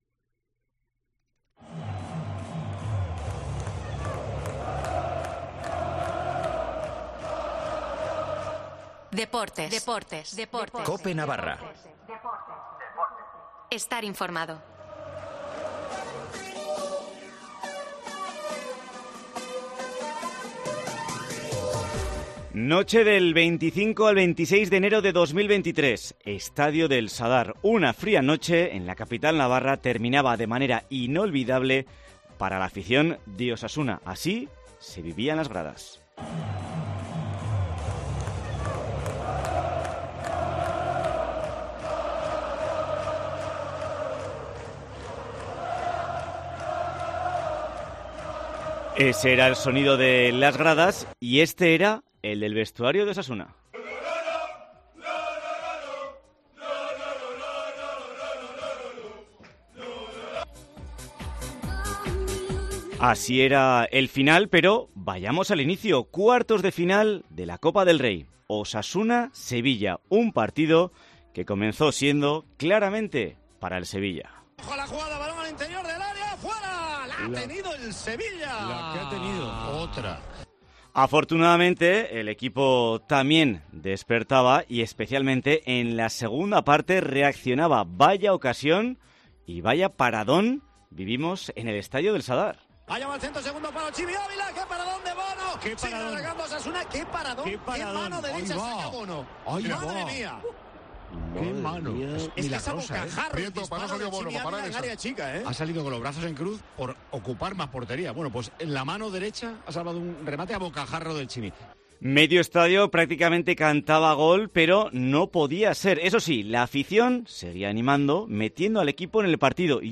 Escucha los goles y mejores momentos del partido contra el Sevilla en Tiempo de Juego